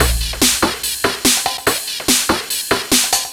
DS 144-BPM A7.wav